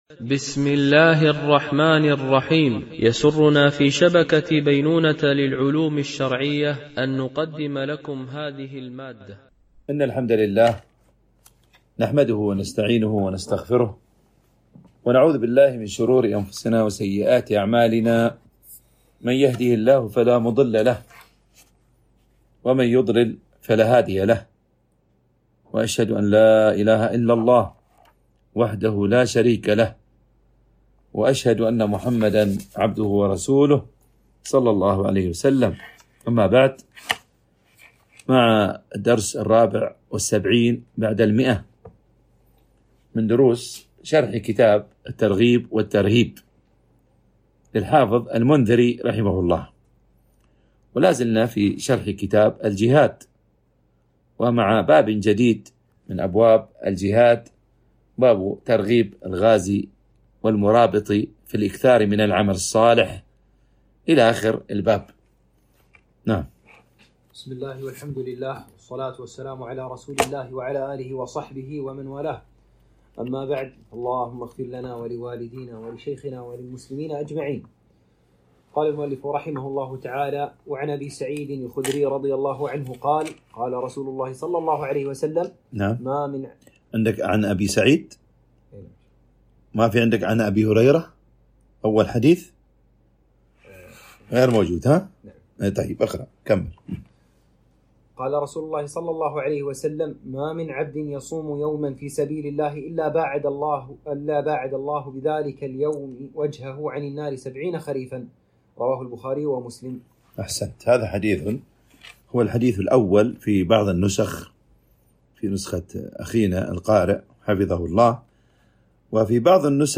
شرح كتاب الترغيب والترهيب - الدرس 174 ( كتاب الجهاد - باب ترغيب الغازي والمرابط من العمل الصالح...